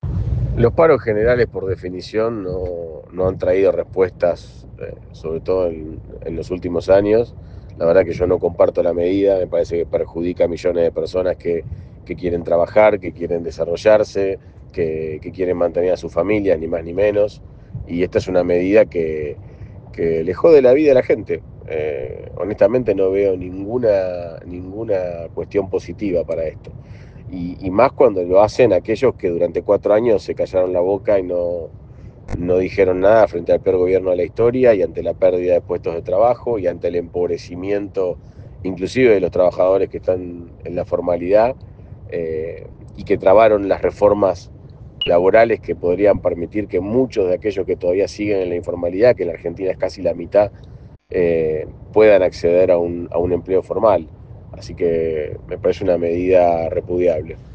Desde Argentina Política, conversamos con diferentes actores afines al gobierno Nacional.